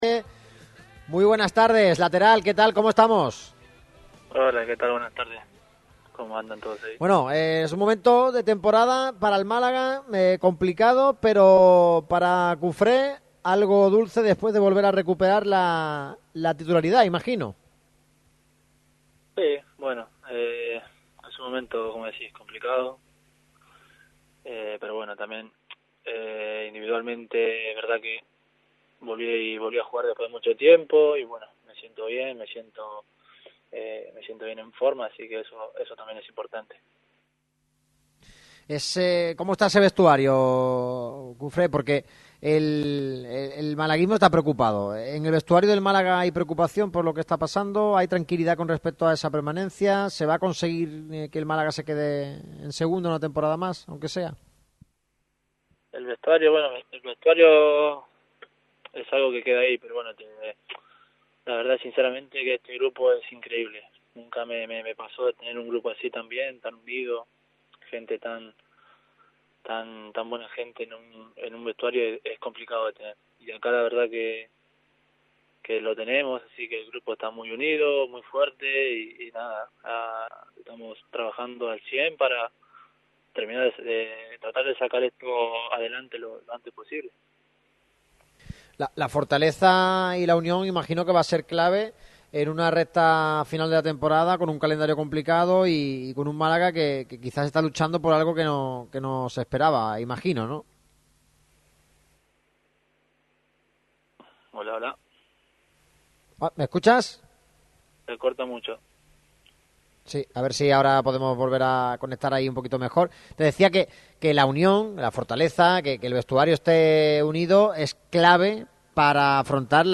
Braian Cufré ha pasado por el micrófono rojo de Radio MARCA Málaga para analizar la situación del equipo y su momento personal.